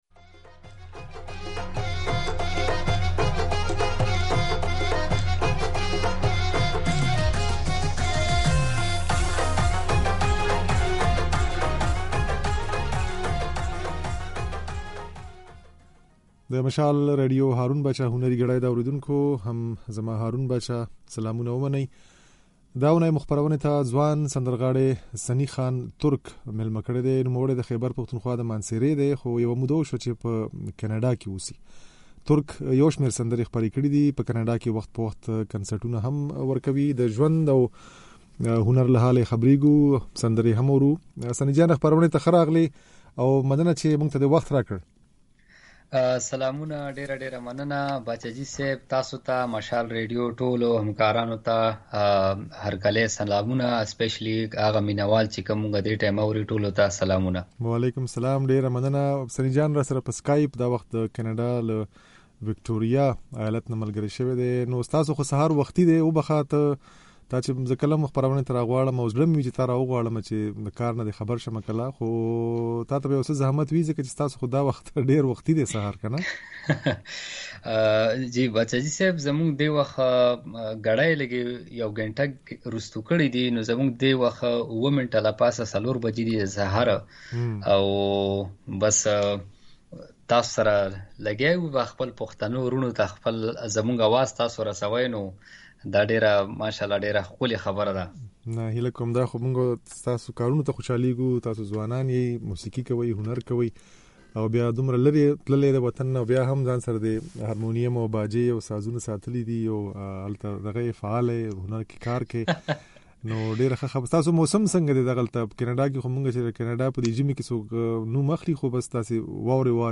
خبرې او ځينې سندرې يې په خپرونه کې اورېدای شئ